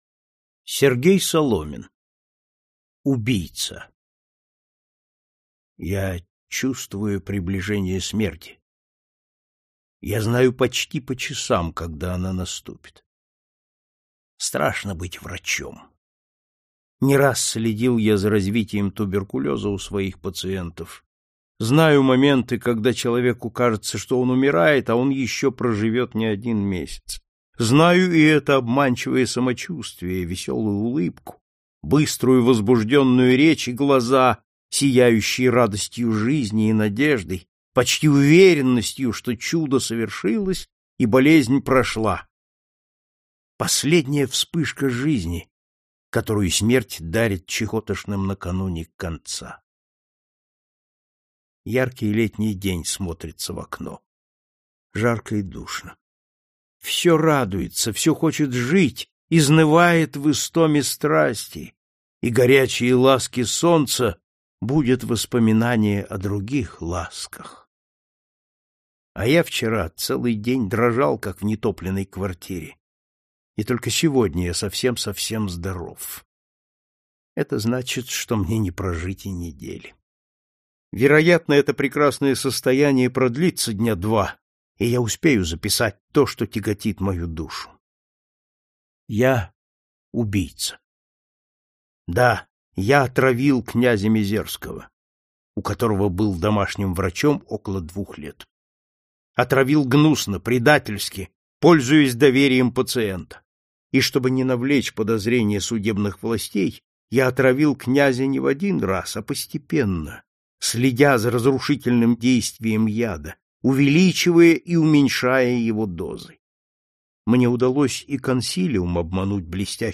Аудиокнига Классика русского детективного рассказа № 4 | Библиотека аудиокниг